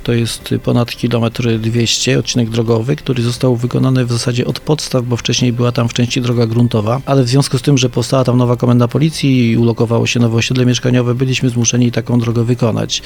Ostatnio do użytku został oddany odcinek między ulicami 11 Listopada i Batalionów Chłopskich. Więcej o inwestycji mówi burmistrz Zwolenia Arkadiusz Sulima: